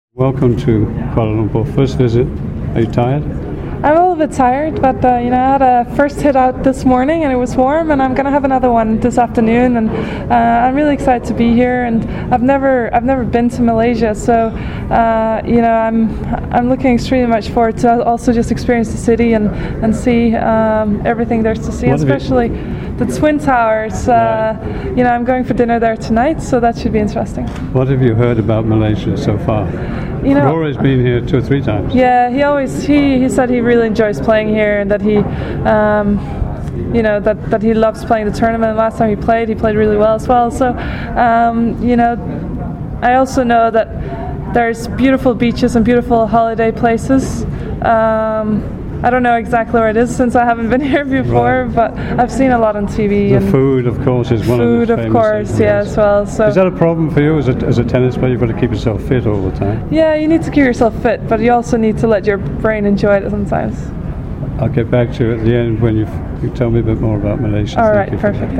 MGTA interviews Caroline Wozniacki